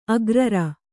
♪ agrara